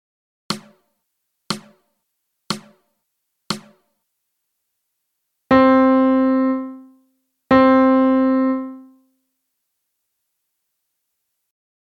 In this lesson you will practise rhythms which use the following rhythmic patterns:
Quarter notes:
Quarter note rests:
Here are some example rhythms.